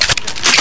Cocking.ogg